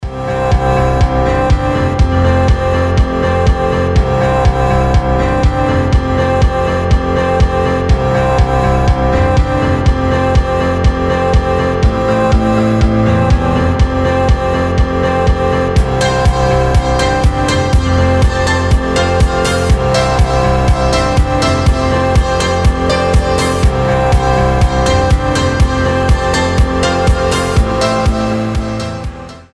• Качество: 320, Stereo
красивые
мелодичные
Electronic
спокойные
без слов
пианино
Приятная музыка без слов